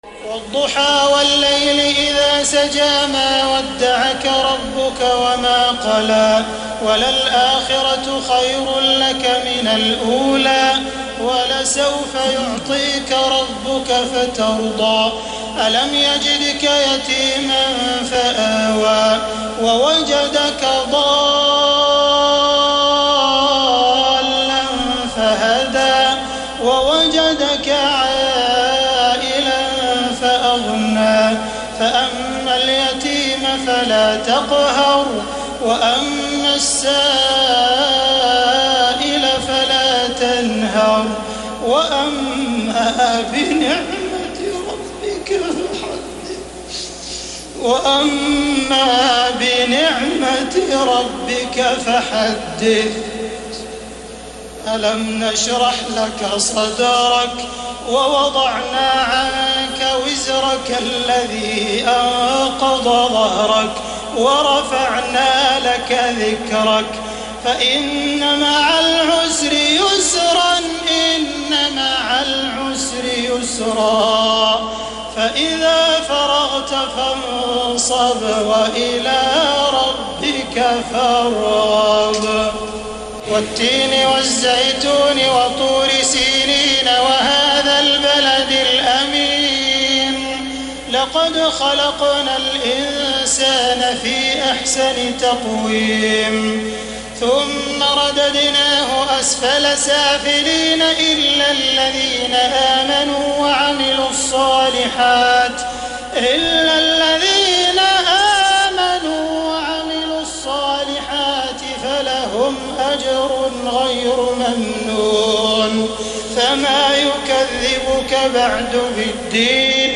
تراويح ليلة 29 رمضان 1432هـ من سورة الضحى الى الناس Taraweeh 29 st night Ramadan 1432H from Surah Ad-Dhuhaa to An-Naas > تراويح الحرم المكي عام 1432 🕋 > التراويح - تلاوات الحرمين